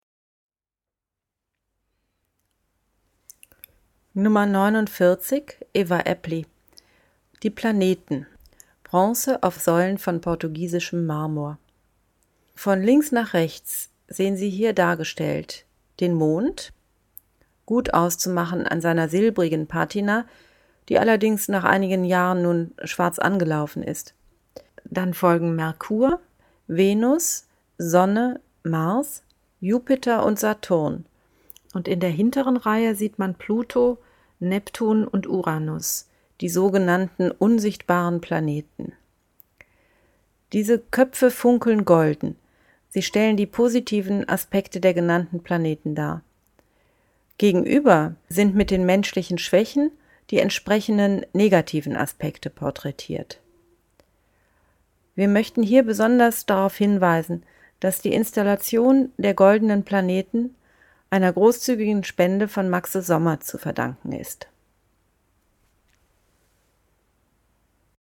audioguide_49_aeppli_planeten_giardino-daniel-spoerri.mp3